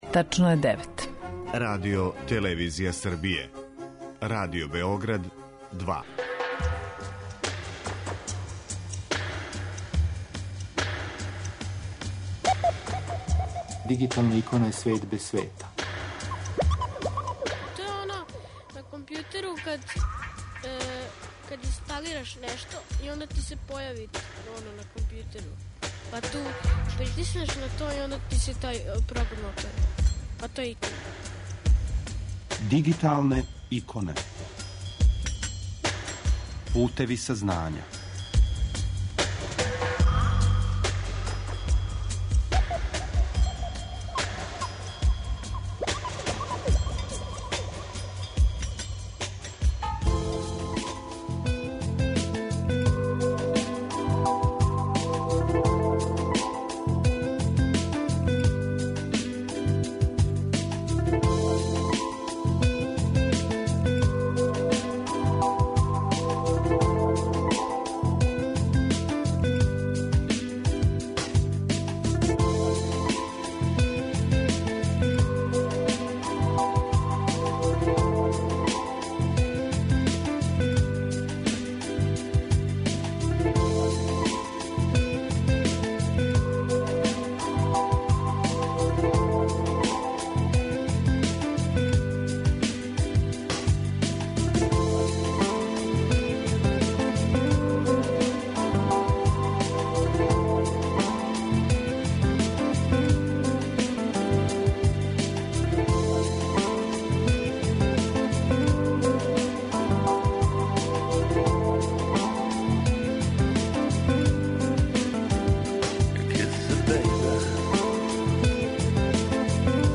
У данашњој емисији Дигиталне иконе чућете белешке са првог дана скупа.